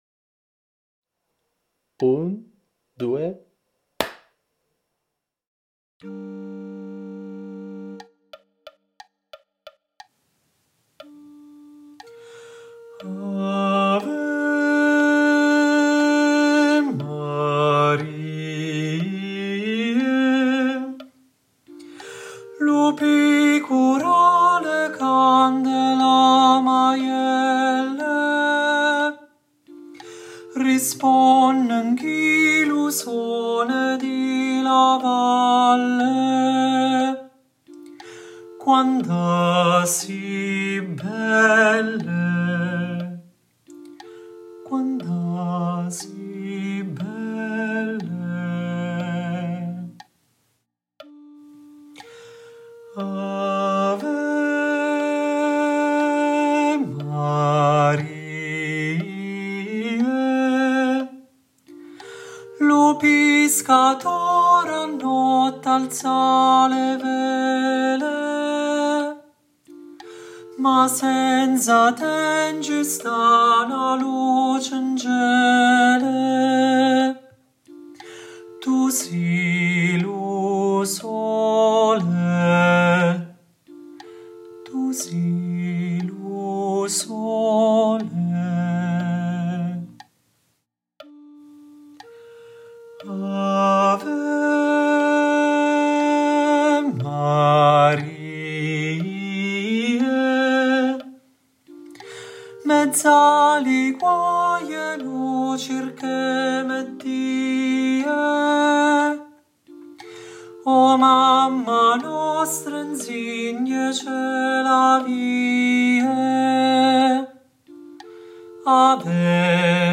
🎧 Voce guida
Tenori
• ⚠ Nella traccia guida sentirai una battuta con il tempo a voce, nel "battere" successivo il ciak di inizio (batti una volta le mani come in un "ciak cinematografico"). Avrai un'altra battuta vuota nella quale sentirai l'intonazione della nota di attacco, una ancora di metronomo e quindi inizierai a cantare.